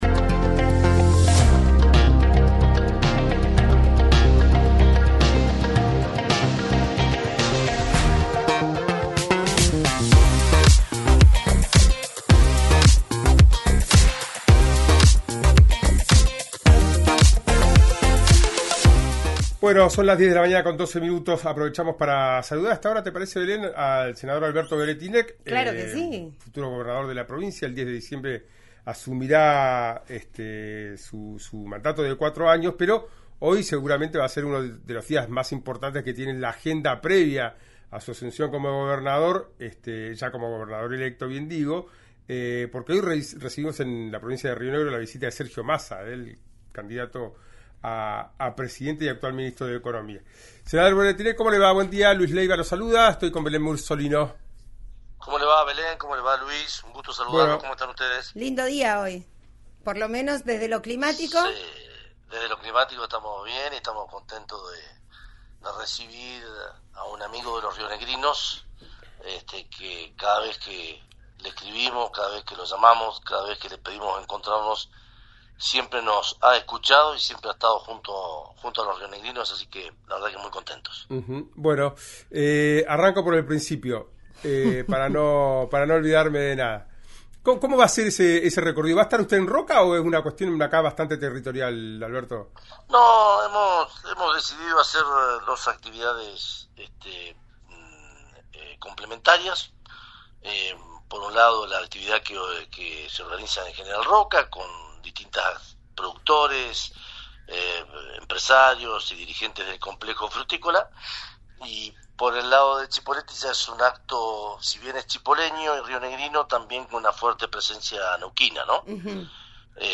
En una entrevista exclusiva con «Ya Es Tiempo» en RÍO NEGRO RADIO, Alberto Weretilneck, senador y gobernador electo en Río Negro, expresó su satisfacción por la visita de Sergio Massa a Cipolletti, calificándolo como un «amigo de los rionegrinos».